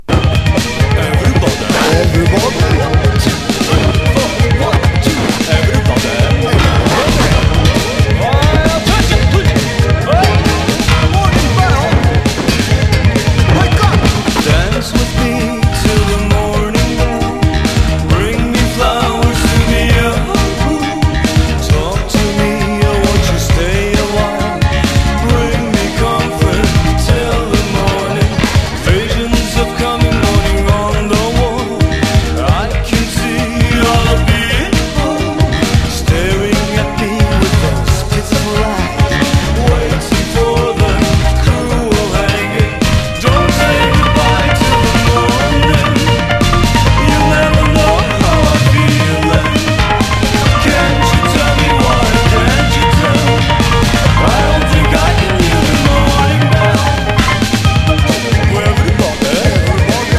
TANGO
斬新でいて多彩なアレンジが楽しませてくれます！